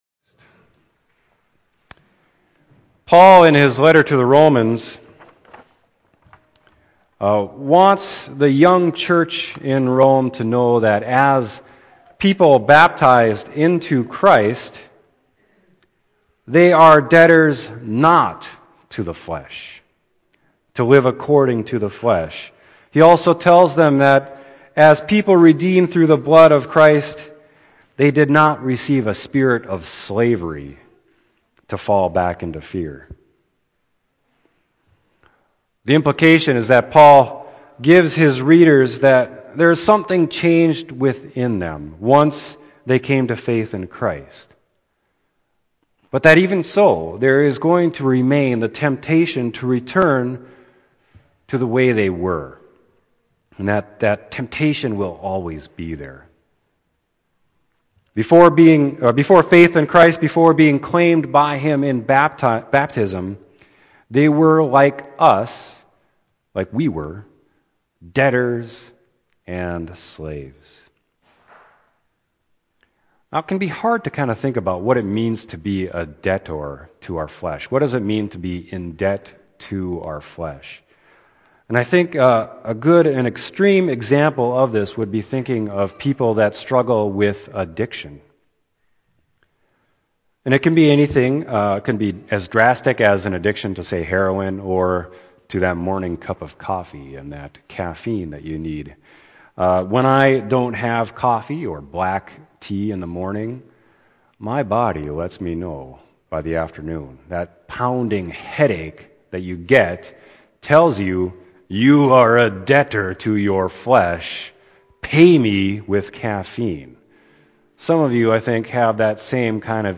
Sermon: Romans 8.12-17